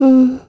Ripine-Vox_Think_kr.wav